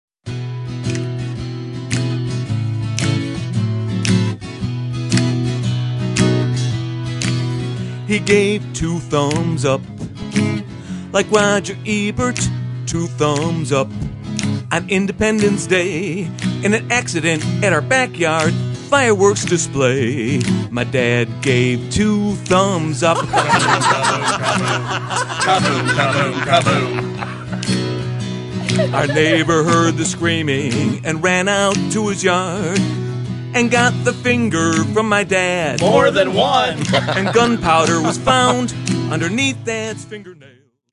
--comedy music